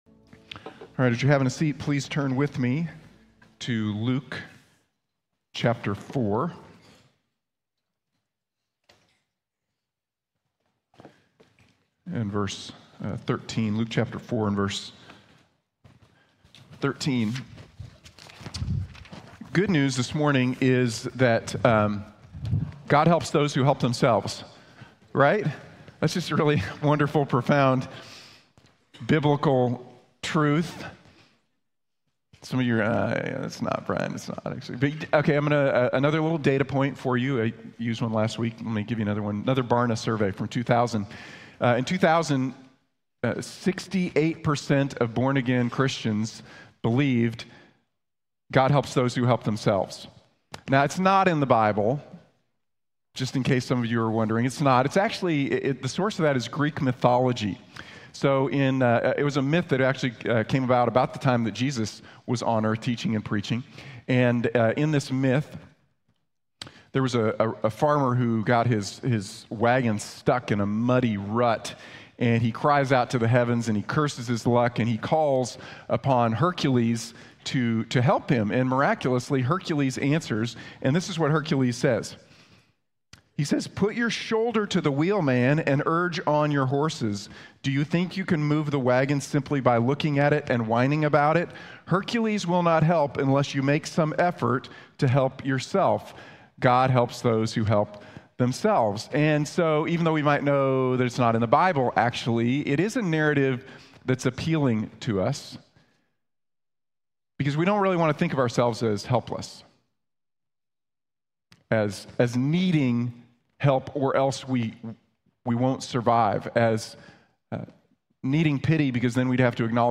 Jesus helps the helpless | Sermon | Grace Bible Church